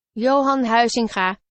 Johan Huizinga (IPA: [ˈjoːɦɑn ˈɦœy̯zɪŋɣaː]
Nl-Johan_Huizinga.oga